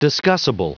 Prononciation du mot discussible en anglais (fichier audio)